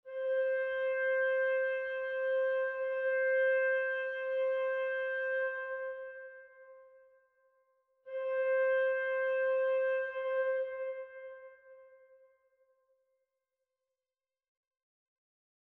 Toward the goal of explaining and demonstrating what reverberation does, I created three WAVE files of the same notes in your example, where the instrument is the Notion 3 bundled Clarinet . . .
(3) This is the Notion 3 bundled Clarinet with IK Multimedia CSR reverberation in the "Large Organ Church" setting, so it is the "very wet" Clarinet:
Notion 3 Bundled Clarinet -- VERY WET -- IK Multimedia CSR Reverb (Large Organ Church) -- WAVE file (2.8MB, approximately 16 seconds)
N3-Clarinet-Large-Church-CSR.wav